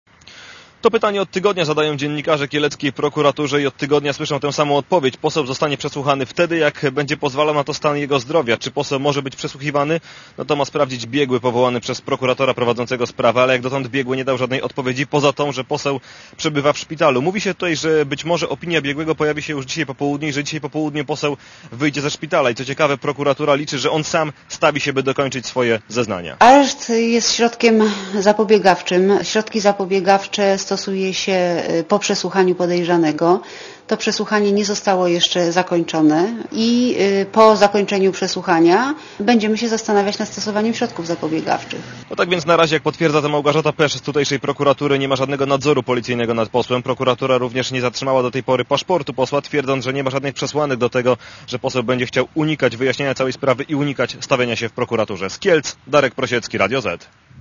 Kiedy poseł może być przesłuchany? O tym reporter Radia Zet (224Kb)